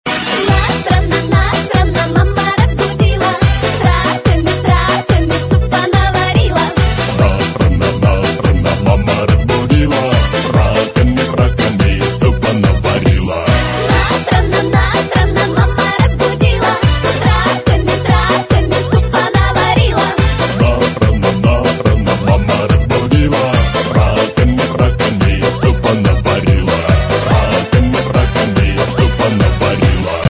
веселые
смешные